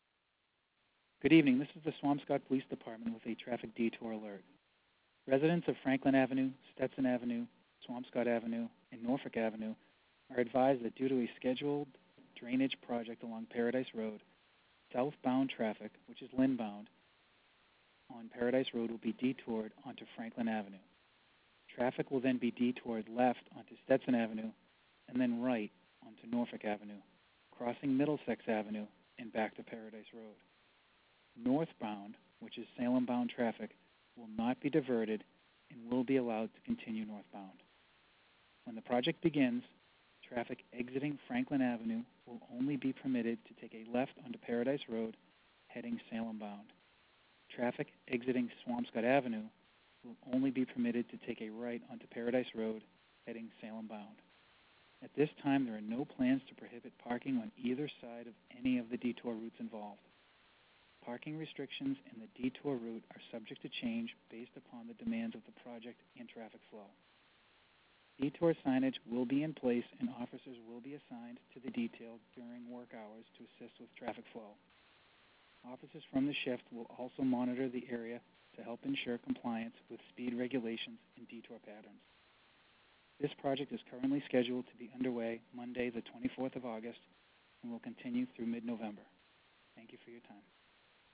Paradise Road Drainage Project / Traffic Detour Call